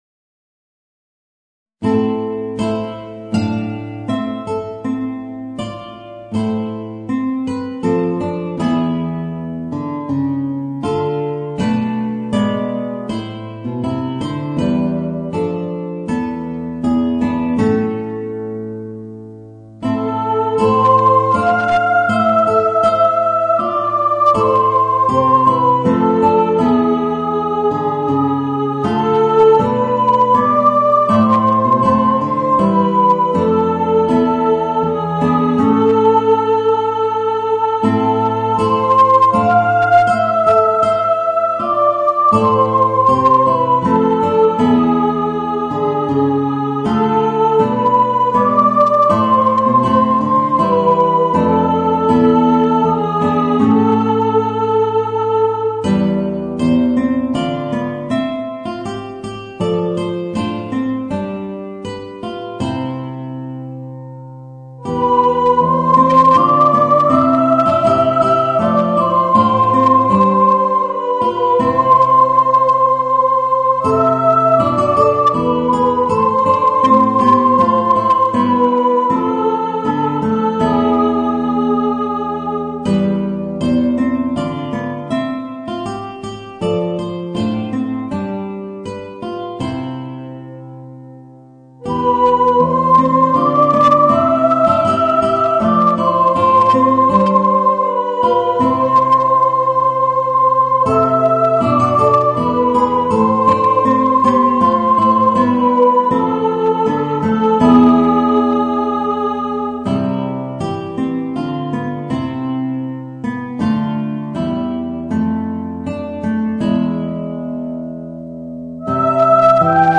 Voicing: Guitar and Mezzo-Soprano